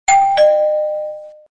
messagealert6.mp3